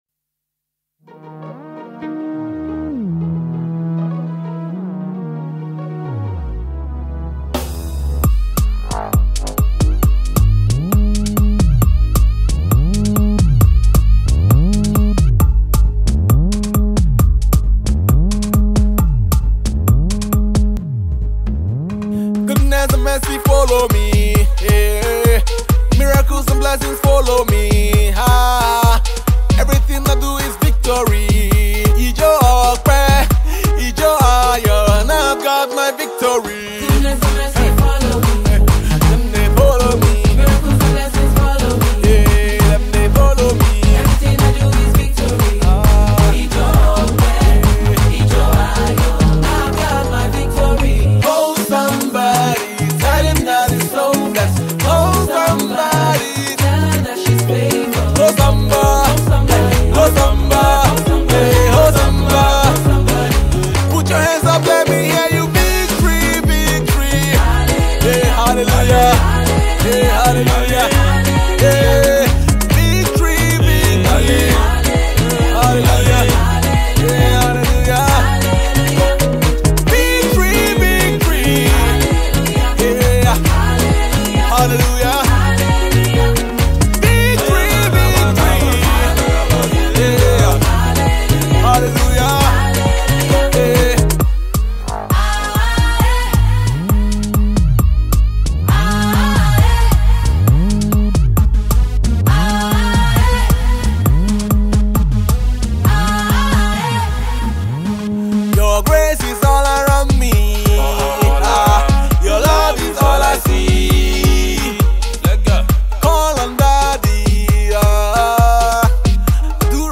Nigerian Gospel Songs
Genre:Gospel